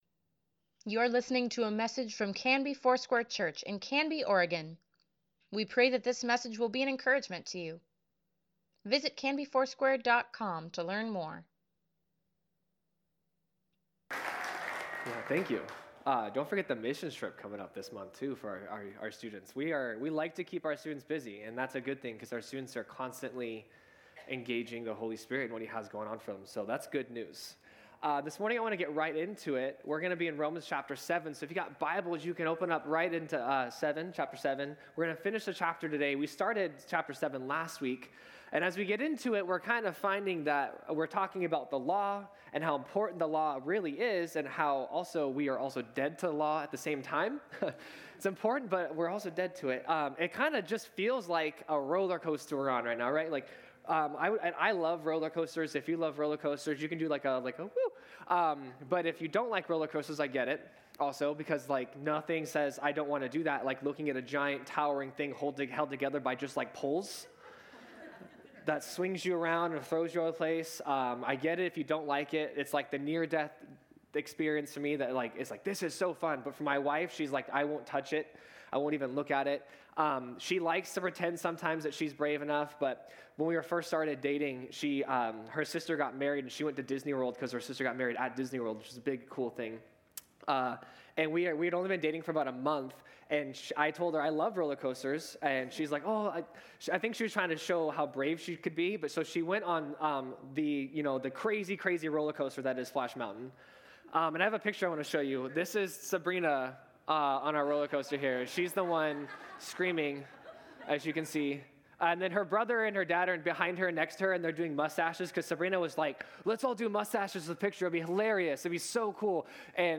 Sunday Sermon | June 2, 2024 | Canby Foursquare Church